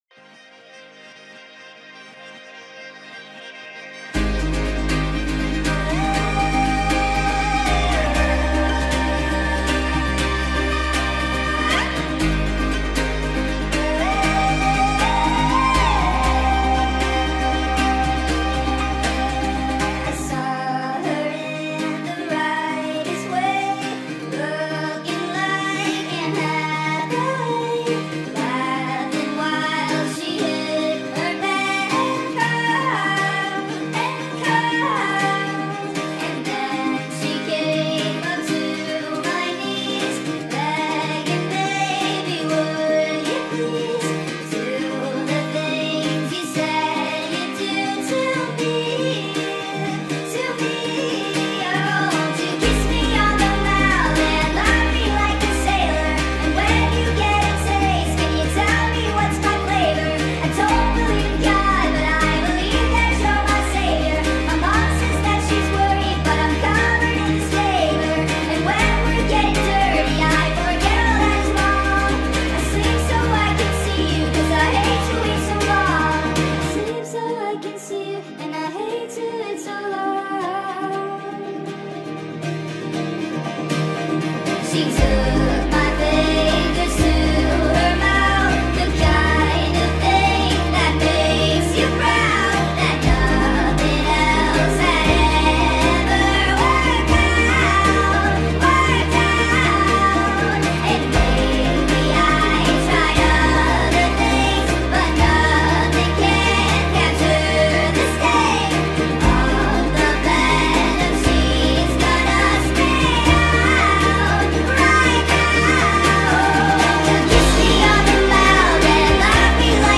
با ریتمی تند شده در نسخه Sped Up